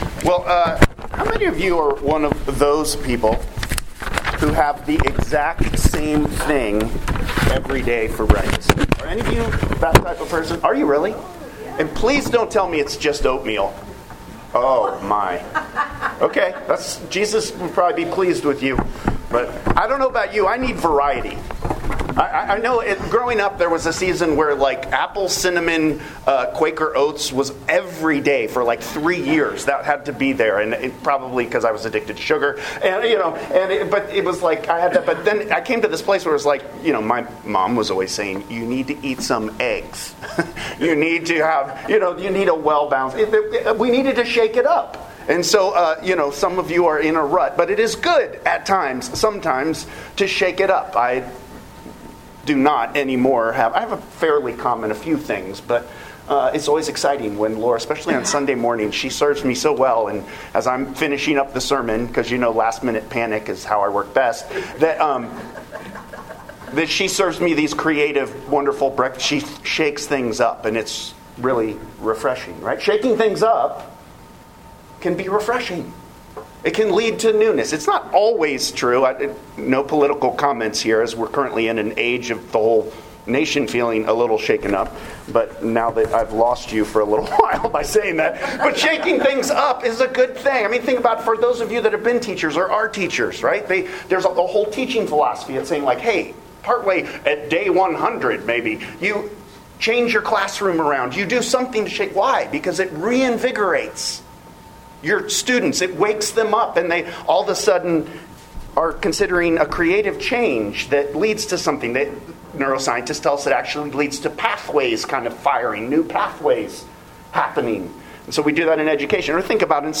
Sermons | Hope Presbyterian Church of Crozet